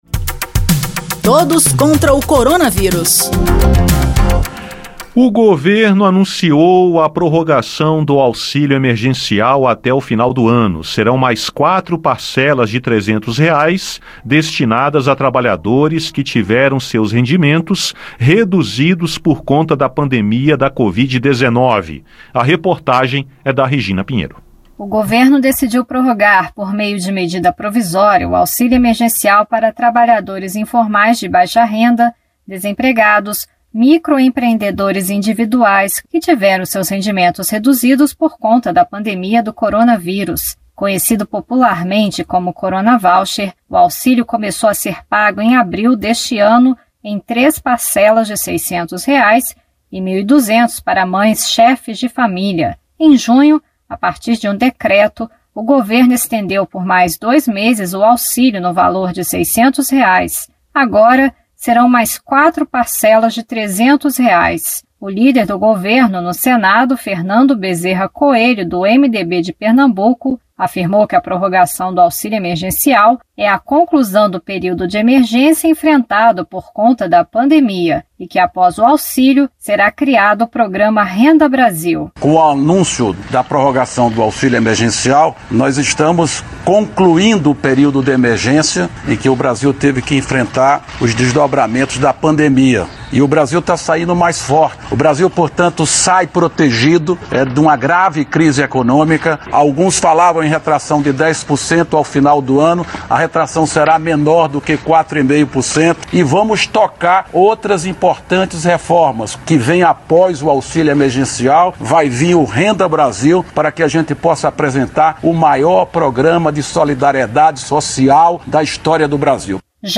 O líder do governo, senador Fernando Bezerra Coelho (MDB-PE), disse que esse é o valor possível. Já o líder do PT, senador Rogério Carvalho (PT-SE), considerou a redução uma crueldade.